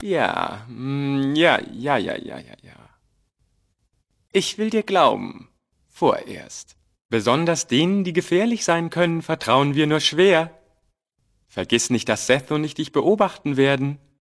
in: Fallout: Audiodialoge Datei : ARA 4.ogg Quelltext anzeigen TimedText Versionsgeschichte Diskussion Tritt unserem Discord bei und informiere dich auf unserem Twitter-Kanal über die aktuellsten Themen rund um Fallout!